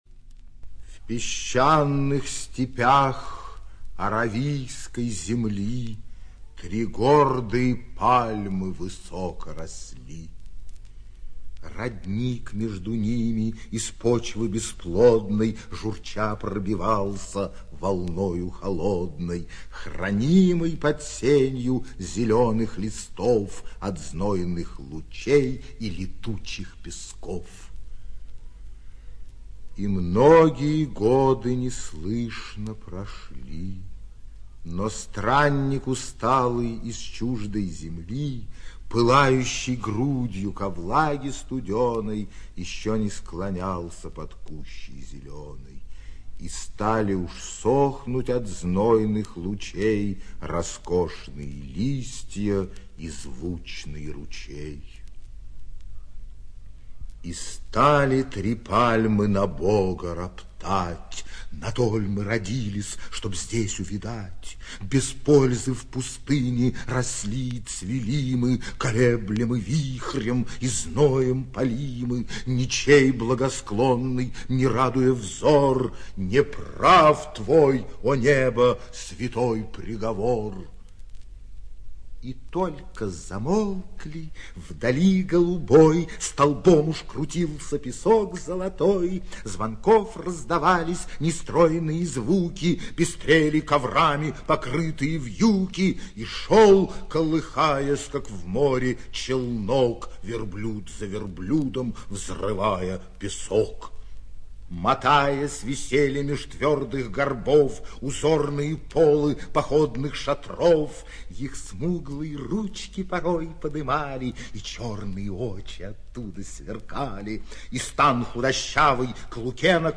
ЧитаетЖуравлев Д.
ЖанрПоэзия